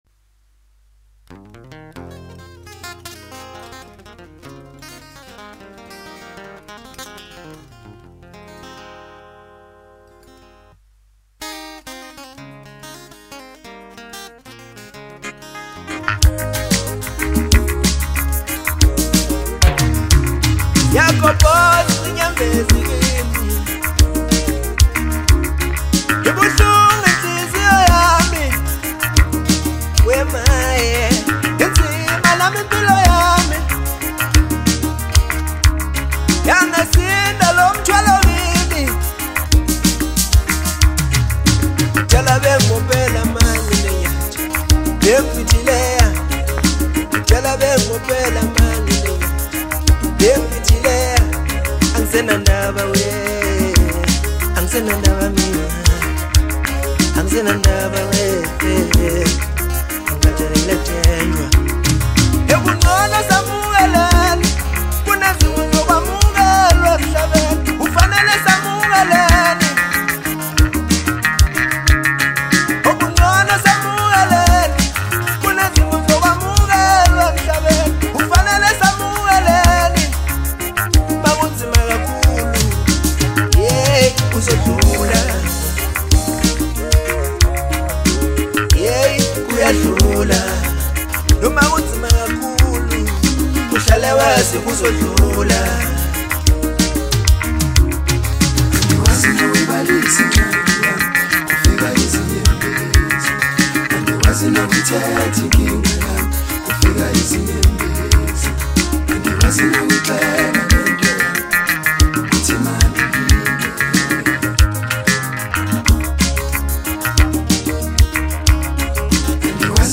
December 29, 2025 admin Maskandi 0